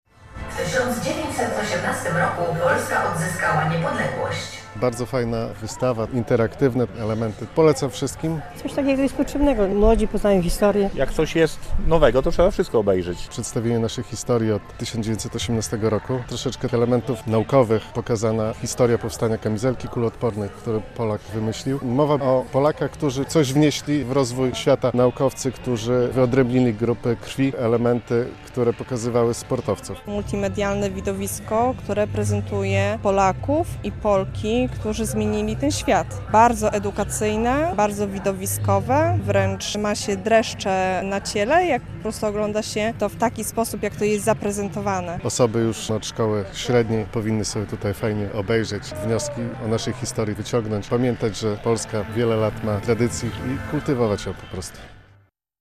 "Iskry Niepodległej" w Podlaskiem - relacja